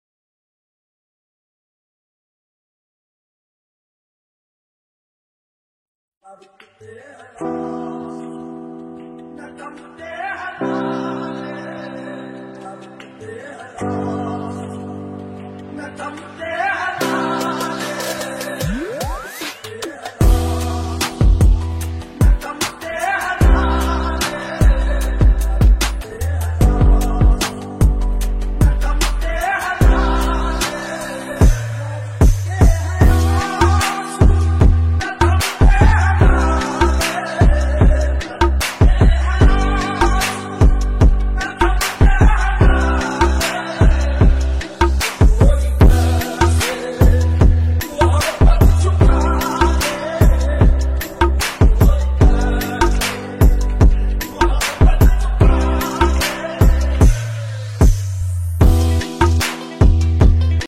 New Remix Qawali